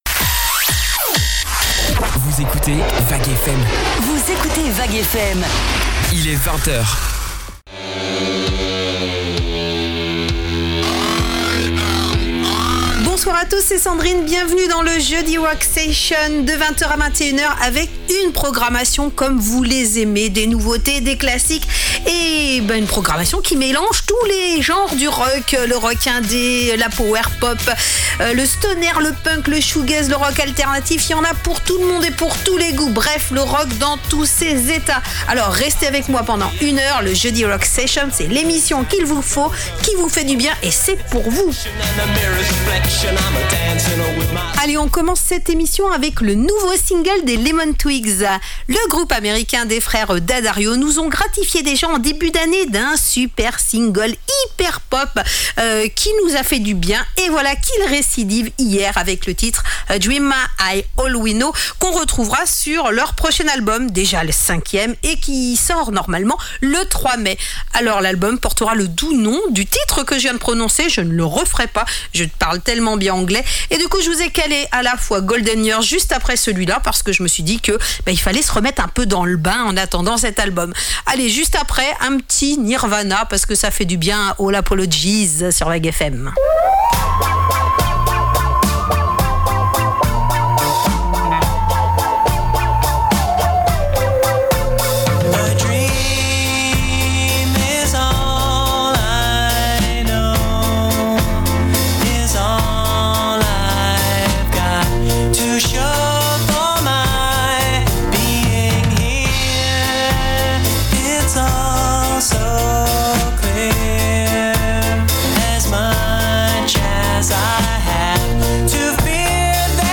Retrouvez l'émission Jeudi Rock Session